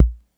606bass.wav